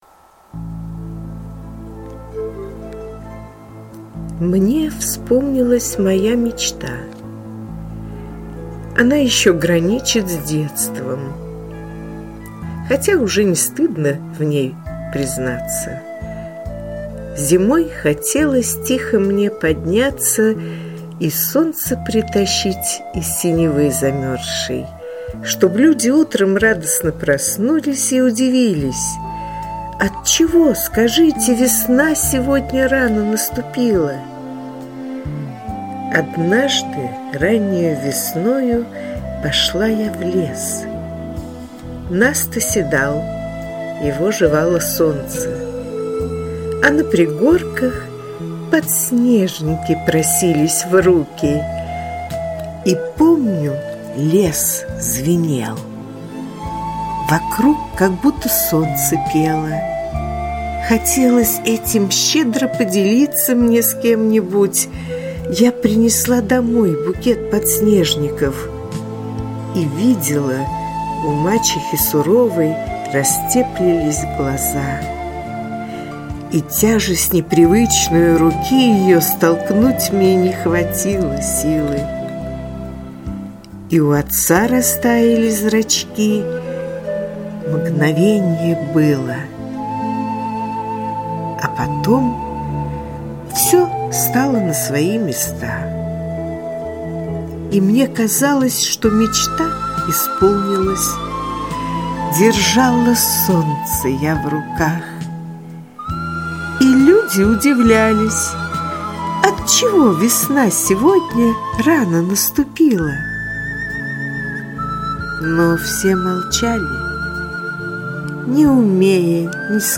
• Жанр: Декламация